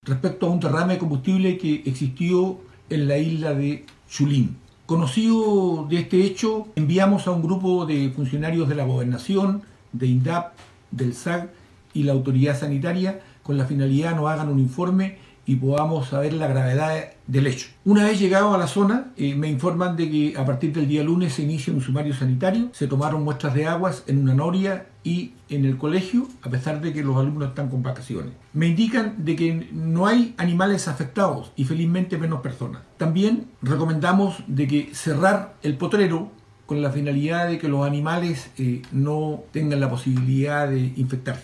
Así lo ratificó el gobernador de la provincia de Palena Osvaldo Oelckers, quien señaló que tal determinación la tomó la delegación de funcionarios intersectoriales que estuvieron el día sábado en el sector, verificando la cuantía de la contaminación.